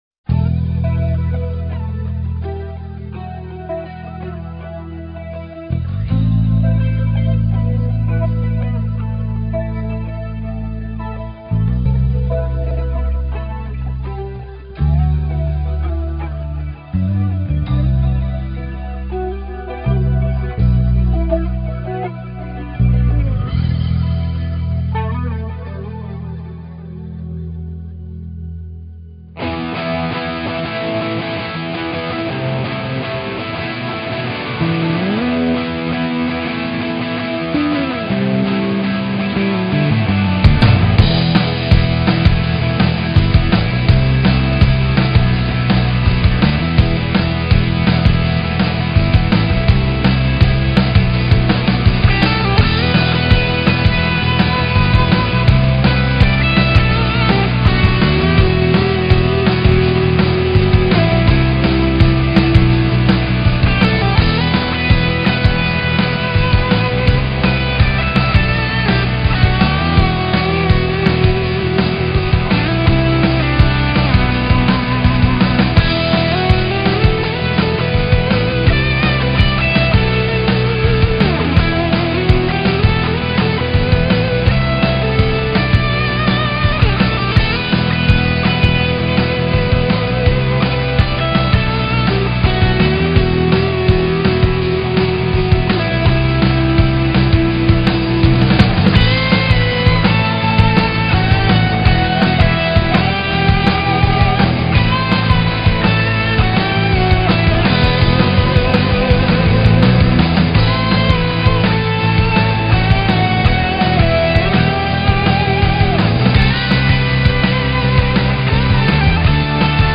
0244-吉他名曲天堂之光.mp3